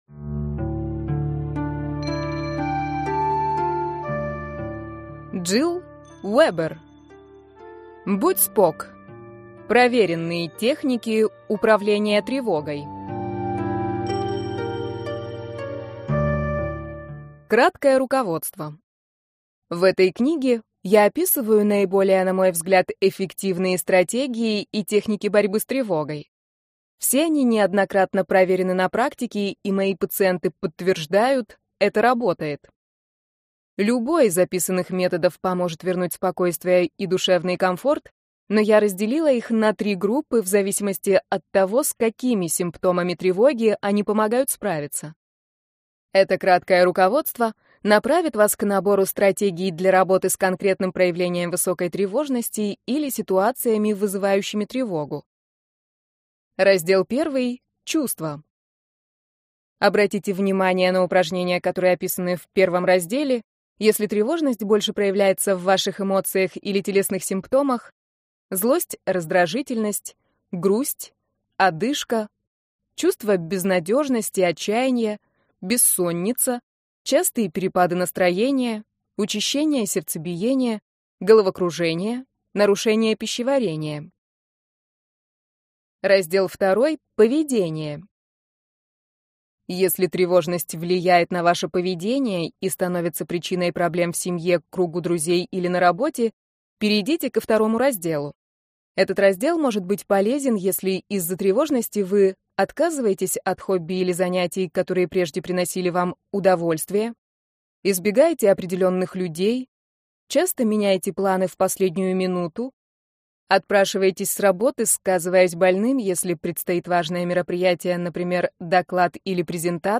Аудиокнига Будь спок. Проверенные техники управления тревогой | Библиотека аудиокниг